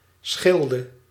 Schilde (Dutch pronunciation: [ˈsxɪldə]